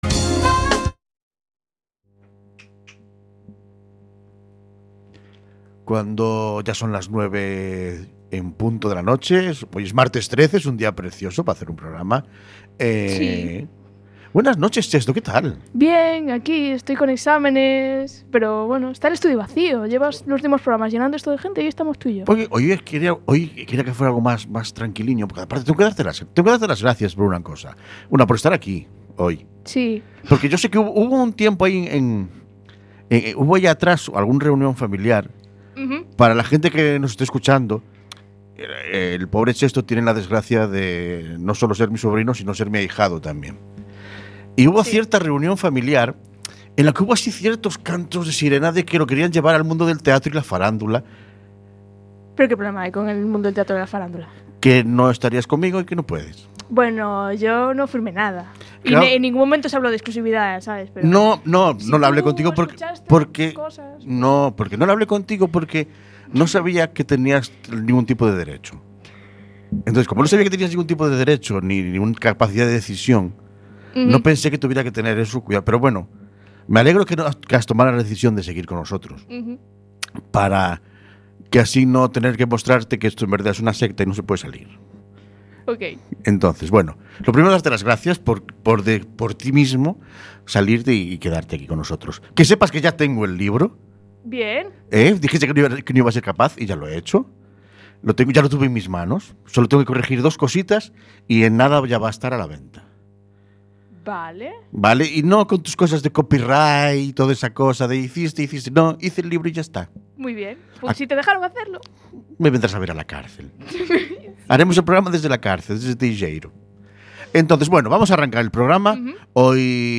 nos sentamos a charlar con una invitada que es pura autenticidad